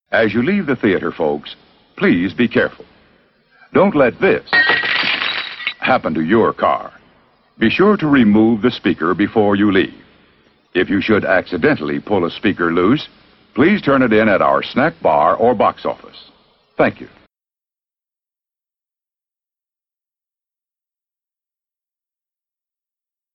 Please Return Your Speaker (Drive-In movie announcement)